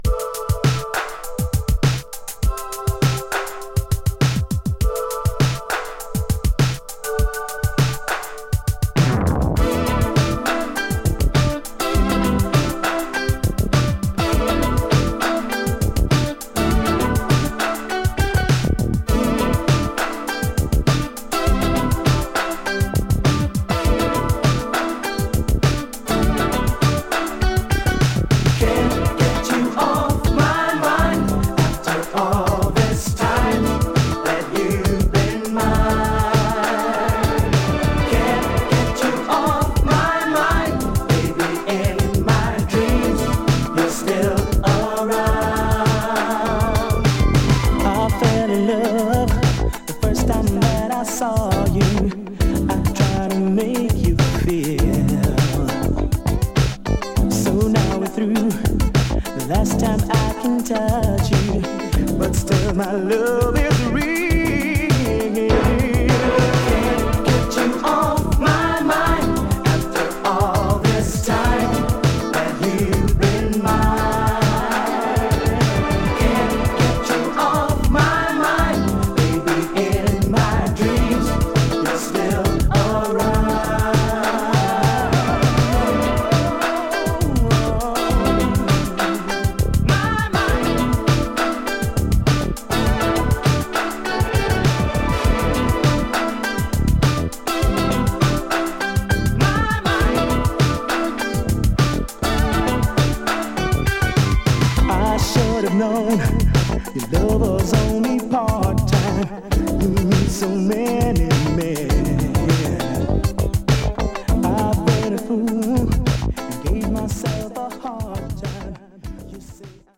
Dutch Urban Boogie!
エモーショナルなヴォーカルが印象的でスローモー&アーバンなシンセ・ブギー！
【NETHERLANDS】【BOOGIE】